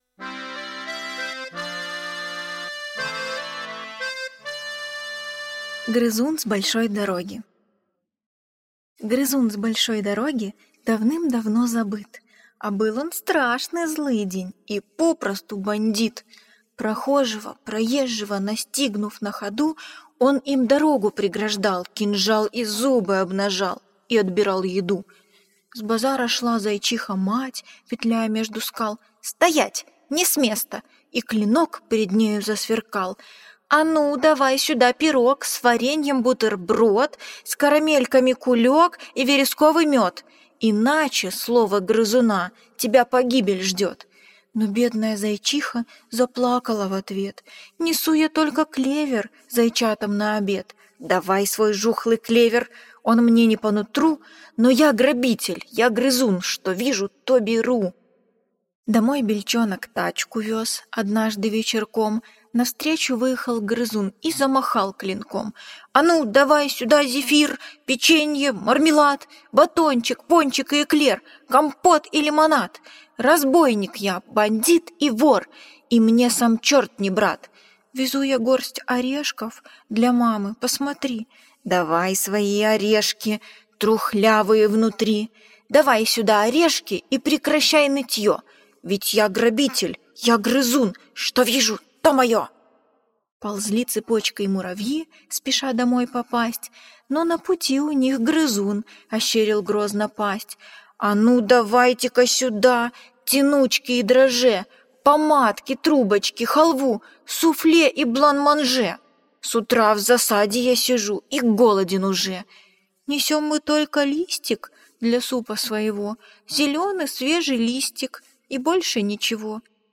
Аудиосказка «Грызун с большой дороги» – Дональдсон Д.